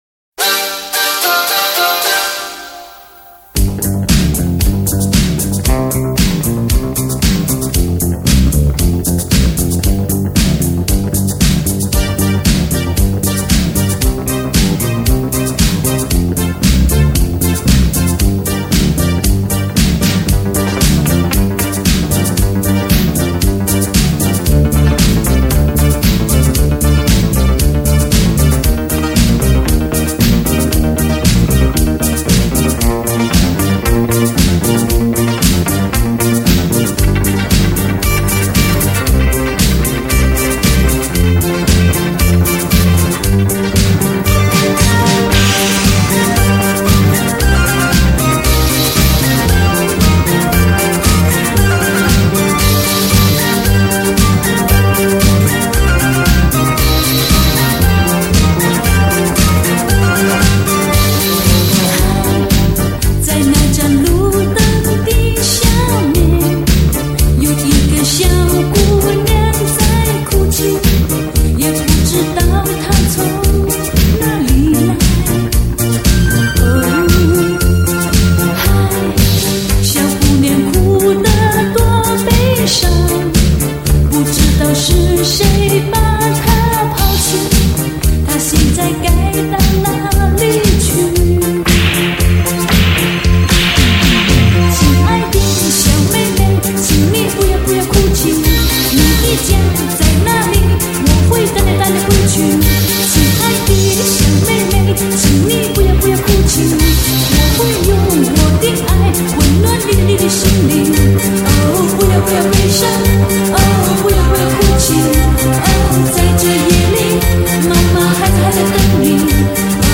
专辑格式：DTS-CD-5.1声道
超级怀旧经典西洋舞曲ITALO Disco！！